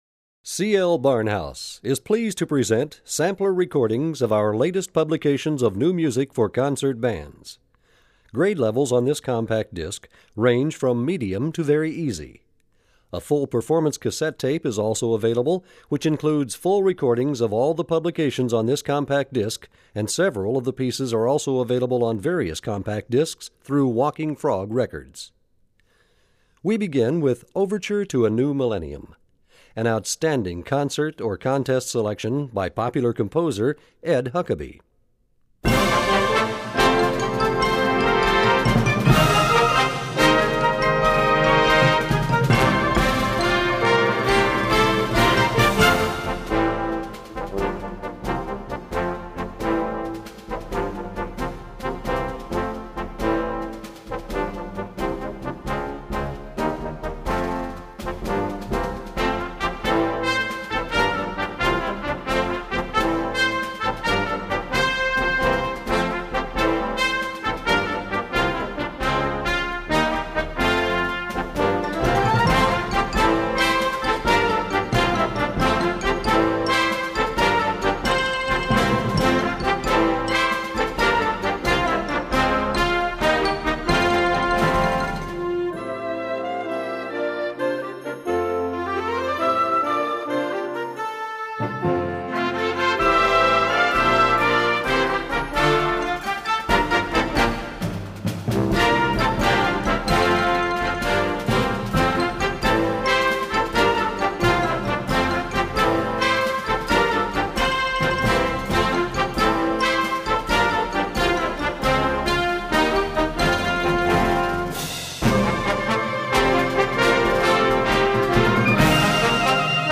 Gattung: Ouvertüre
Besetzung: Blasorchester